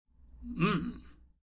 Download Onomatopoeia sound effect for free.